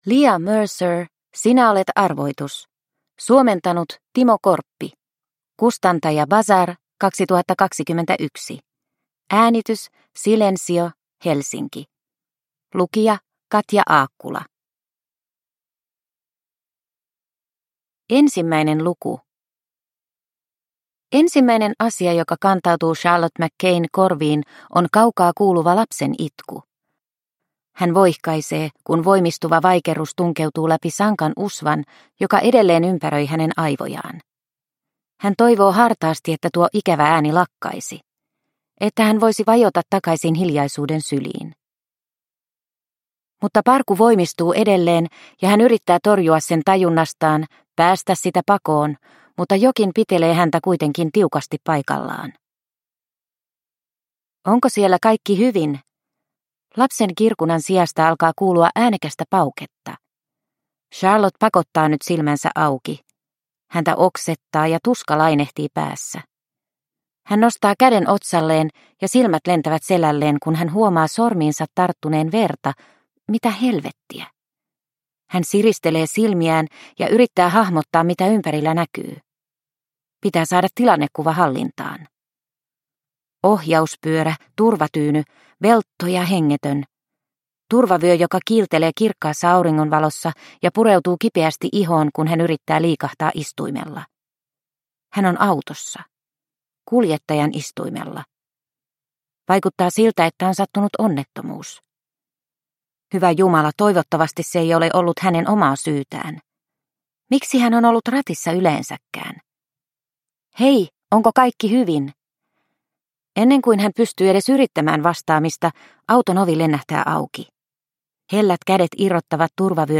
Sinä olet arvoitus – Ljudbok – Laddas ner